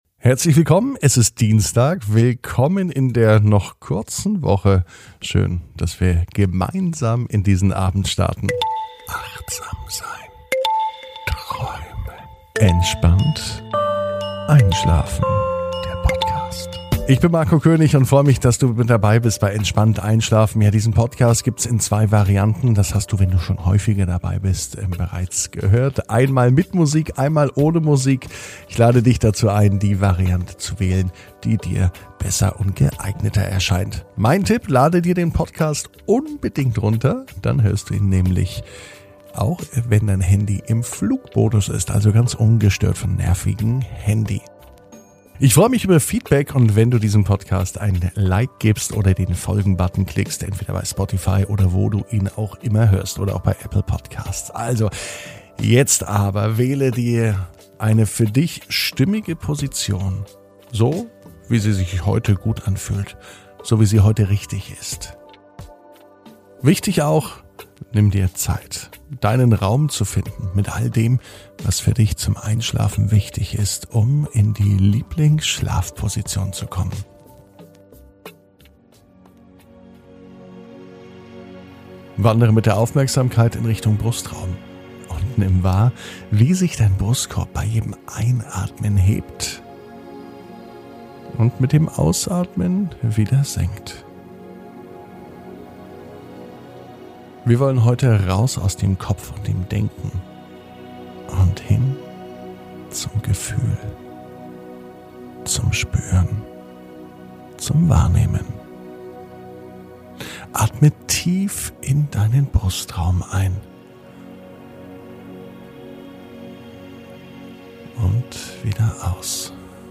Entspannt einschlafen am Dienstag, 25.05.21 ~ Entspannt einschlafen - Meditation & Achtsamkeit für die Nacht Podcast